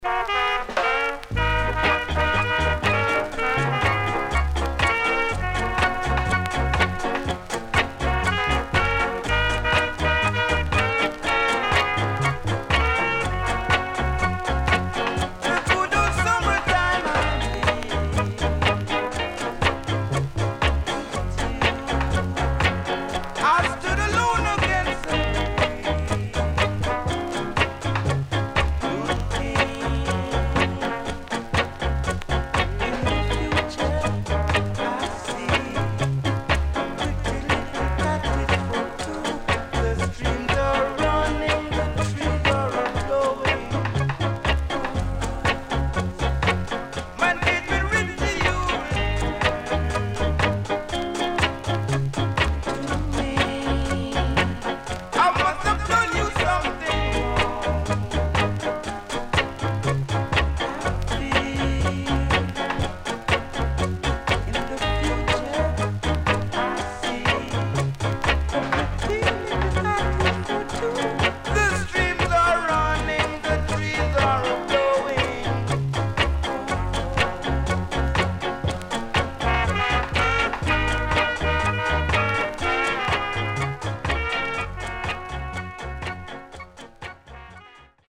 Good Early Reggae Compilation Album
SIDE B:全体的にプレス起因のノイズ入ります。